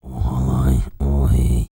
TUVANTALK 5.wav